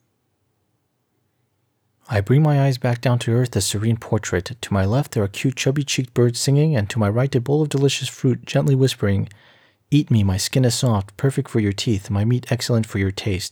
I applied the mastering suite just as it appears in the paper.
Being obsessive, I did apply very gentle noise reduction of the beast (6, 6, 6) and DeEsser at the default values.